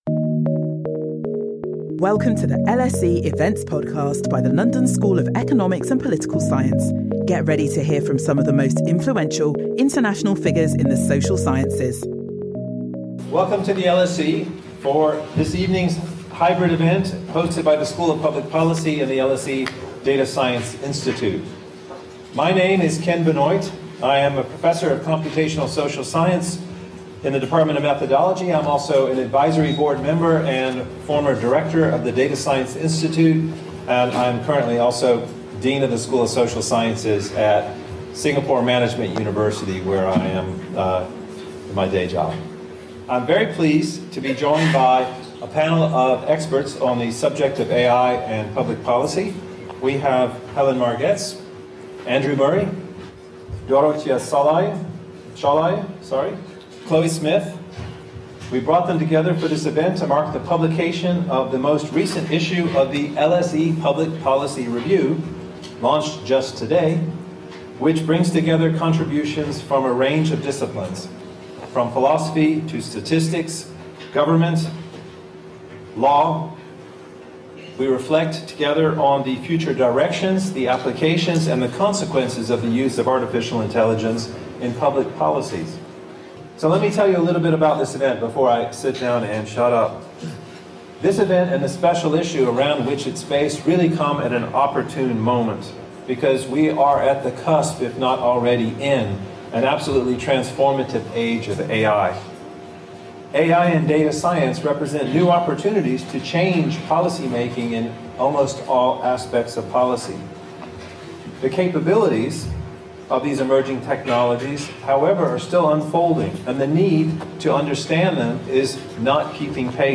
This event marks the publication of the most recent issue of the LSE Public Policy Review, which brings together contributions from a range of disciplines - from philosophy to statistics, government and law - to reflect together on future directions, applications, and consequences of the use of AI in public policies. Join our panellists as they discuss how emerging technologies can transform evidence-based policy development through their analytical capabilities, predictive powers, and real-time monitoring, while also bringing questions around regulation, transparency, accountability and ethic